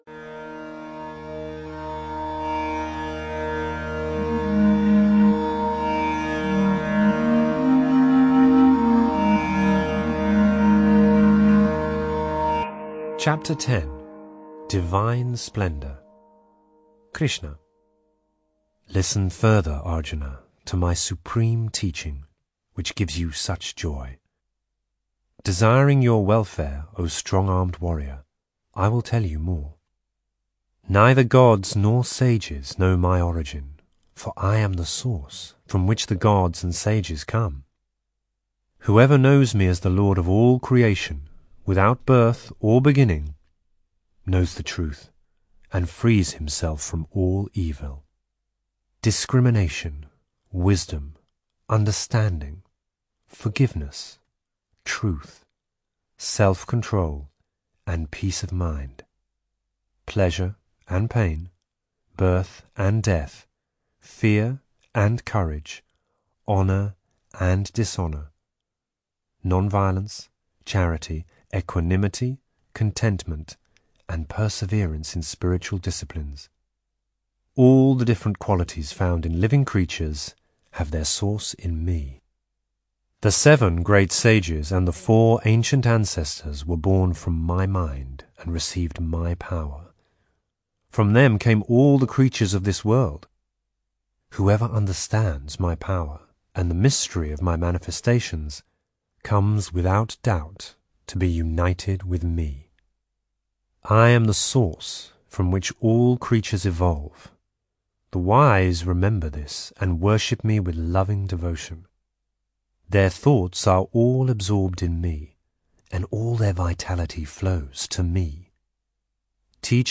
Directory Listing of Audio Books/Bhagavad Gita/Eknath Easwaran(Trans) - The Bhagavad Gita (Unabridged) - 2015/ (SpiritMaji Files)